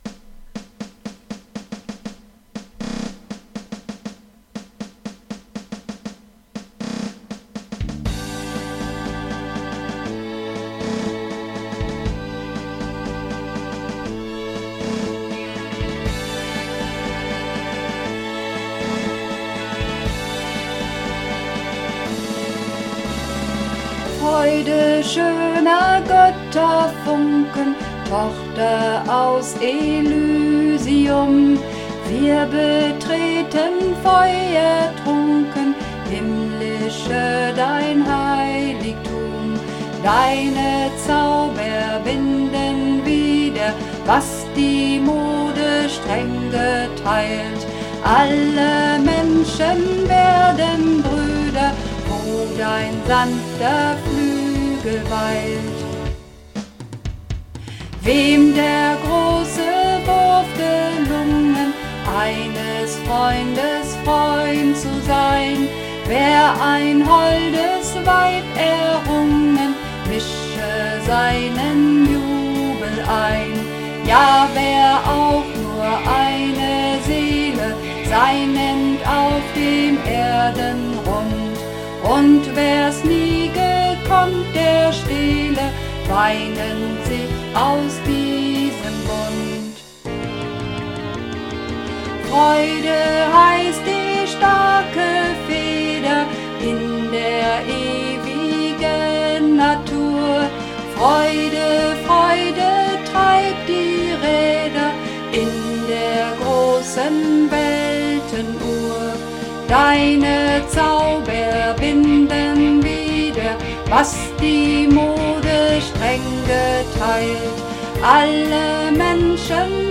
Ode an die Freude (Alt)
Ode_an_die_Freude__1_Alt.mp3